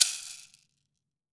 WOOD SHAKER6.WAV